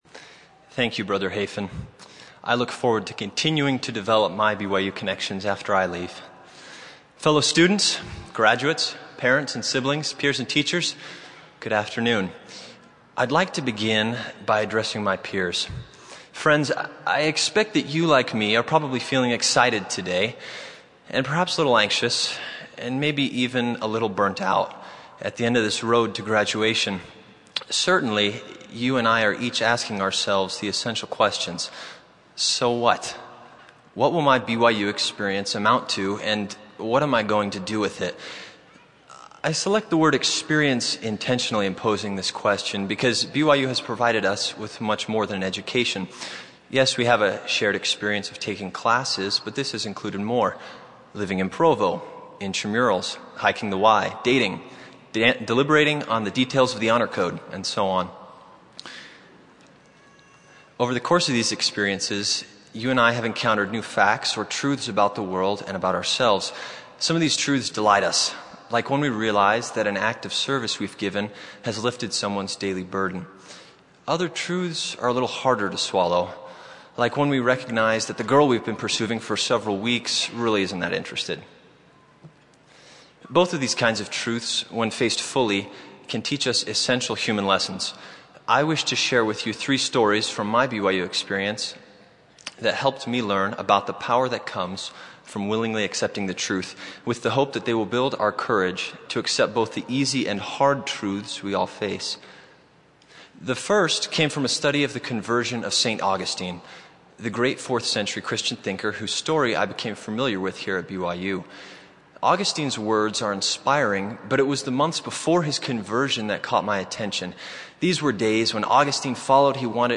Commencement